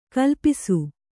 ♪ kalpisu